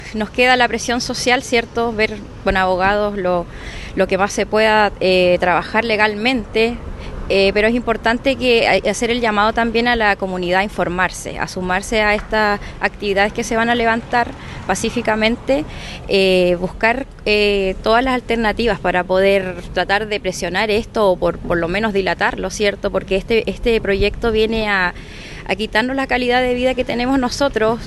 Por su parte, la concejal, Betzabe Riquelme, confía en que la comunidad alzará la voz para manifestar su rechazo a la iniciativa.